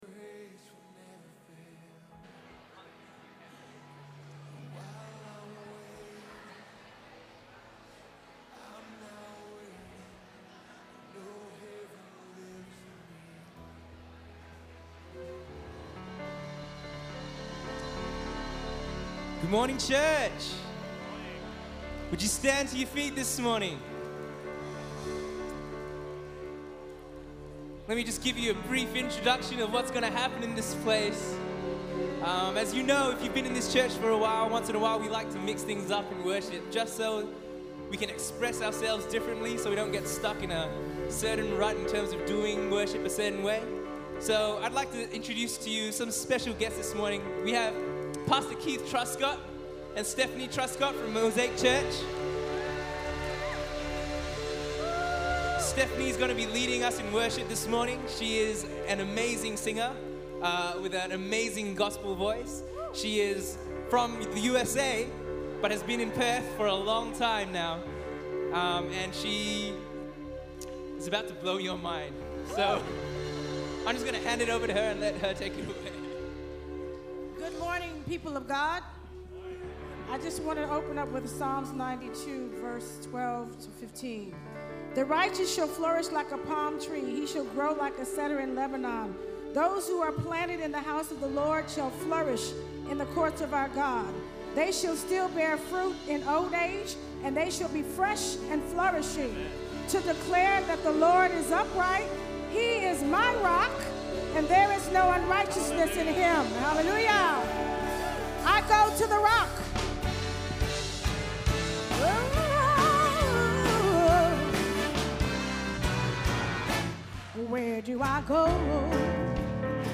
I love Gospel music.